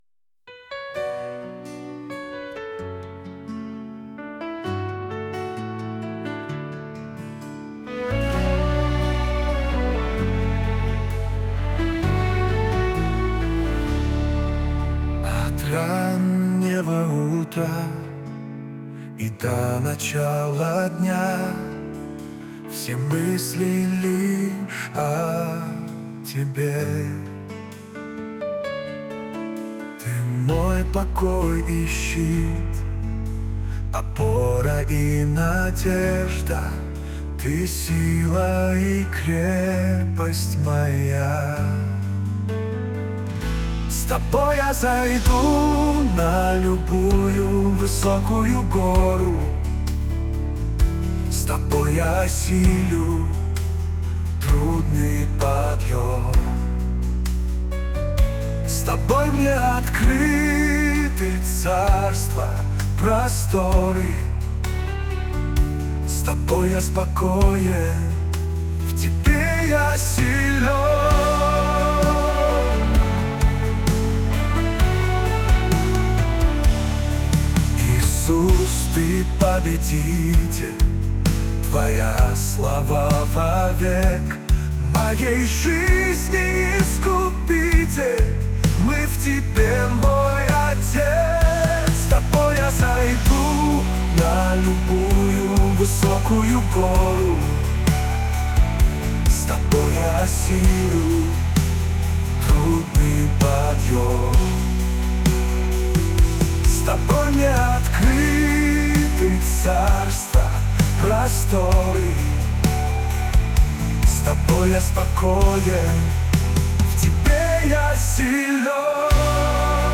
песня ai
196 просмотров 168 прослушиваний 13 скачиваний BPM: 130